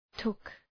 {tʋk} – αόρ. του ‘take’